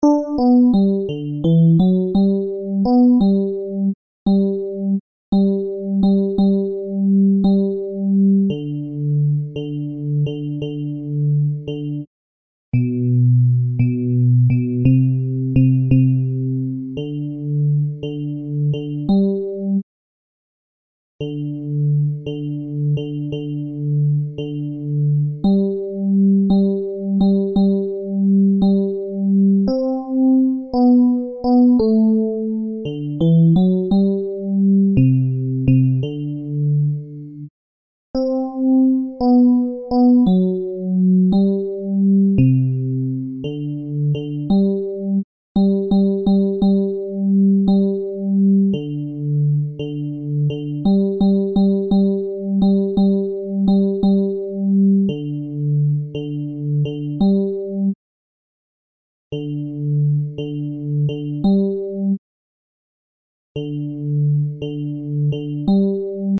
Basses
giovani_liete_basses.MP3